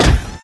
pl_step4-h.wav